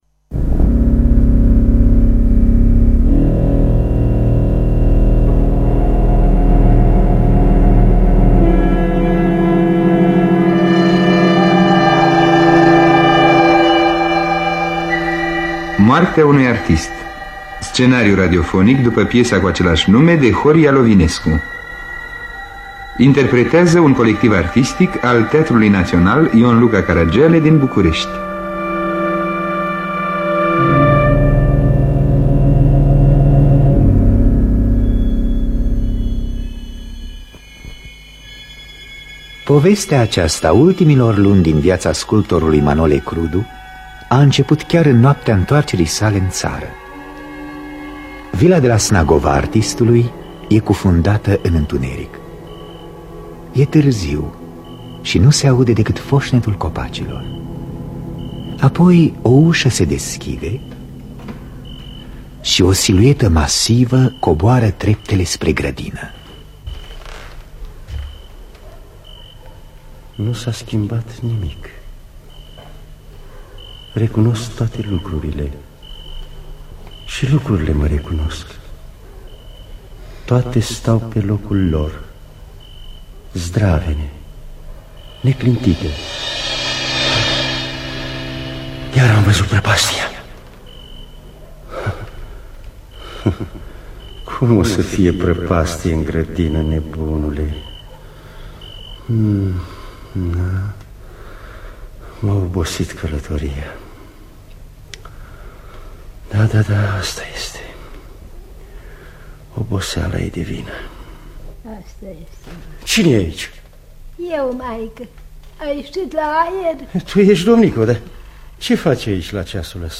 Moartea unui artist de Horia Lovinescu – Teatru Radiofonic Online